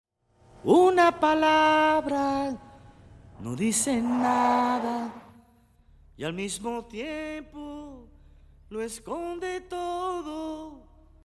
该作品音质清晰、流畅